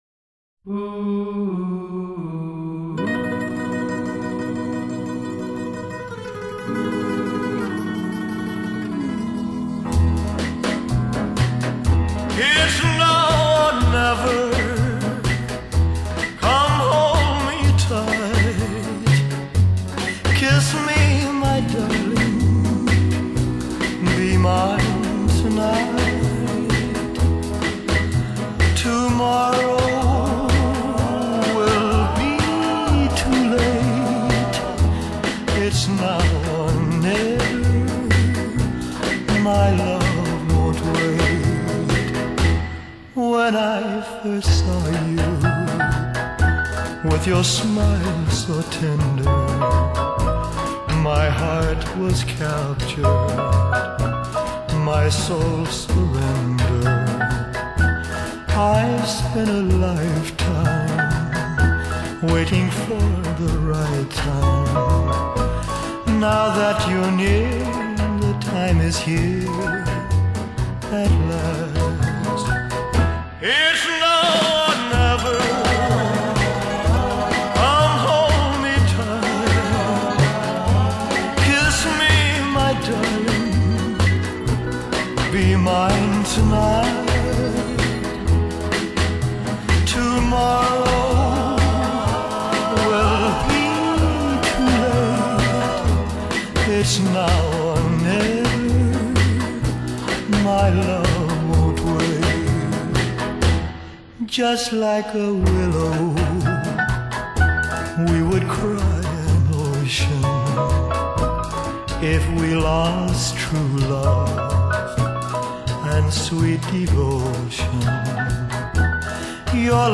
Genre: Pop-Rock / Rock'n'Roll